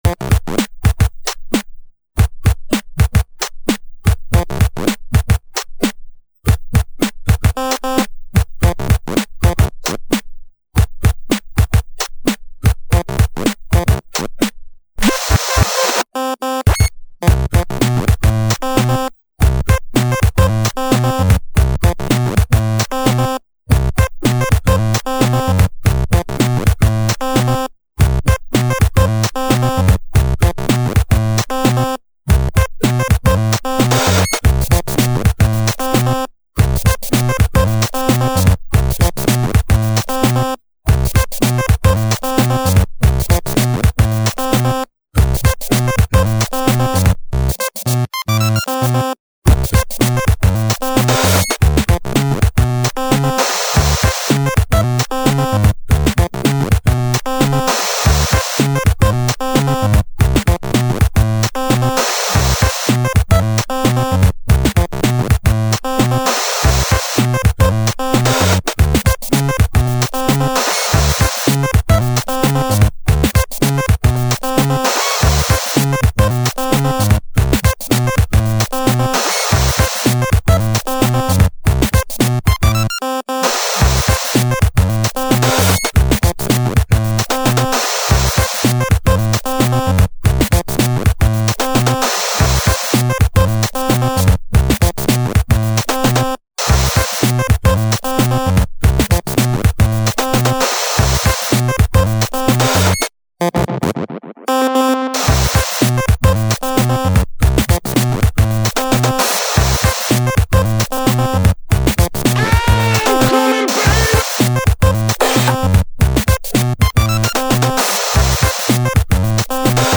2009 19:41 закончил новый геймбой трек